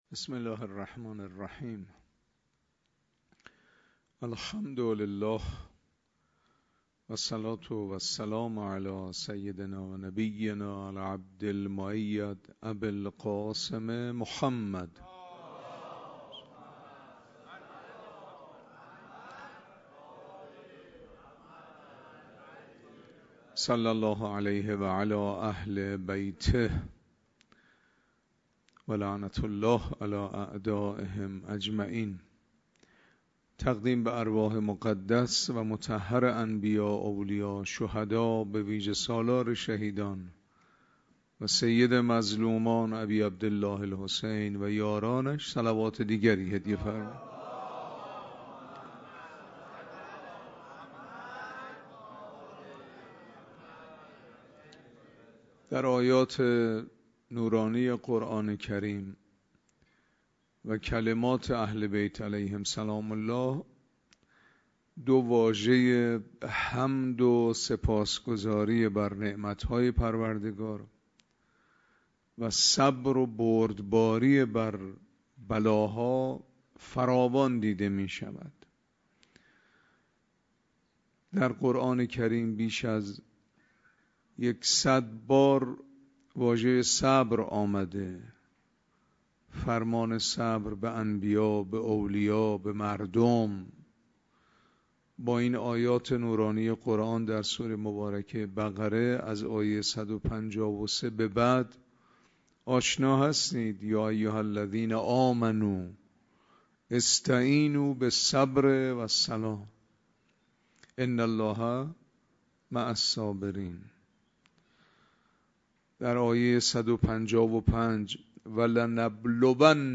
سخنرانی مذهبی